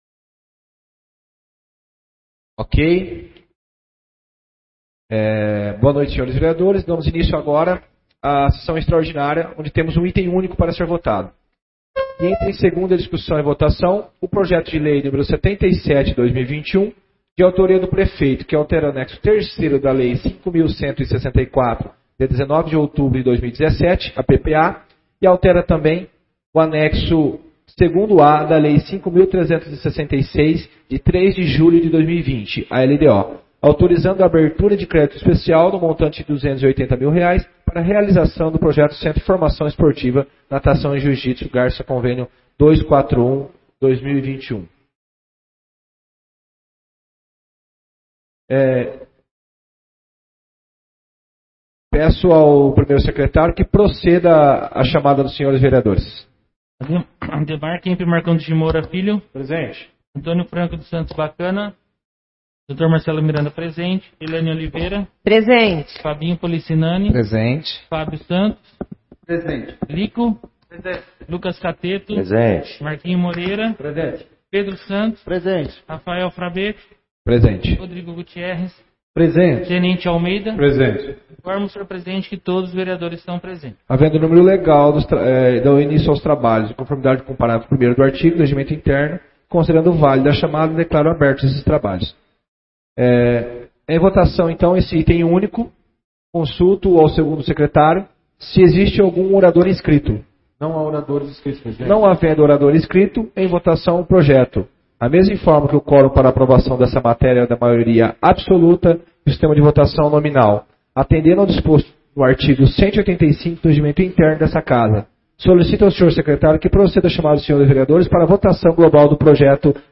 6ª Sessão Extraordinária de 2021